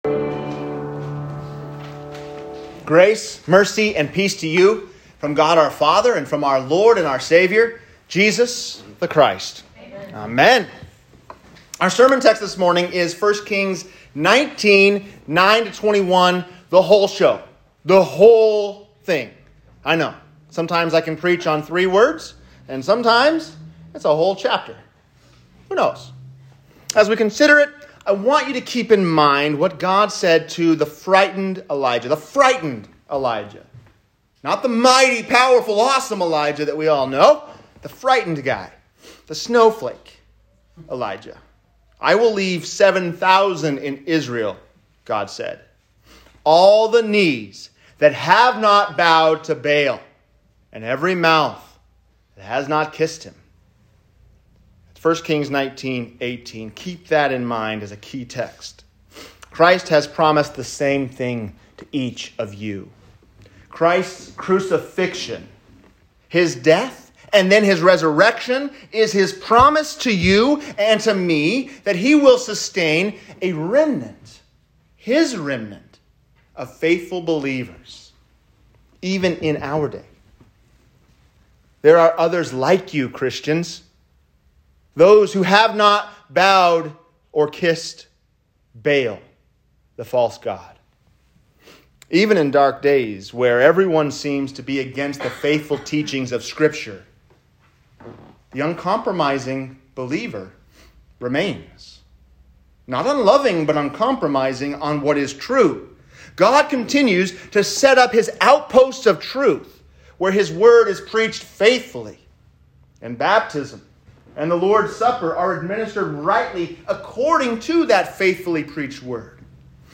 Where Are All the Christians? | Sermon
7-4-21-sermon_trinity-5.m4a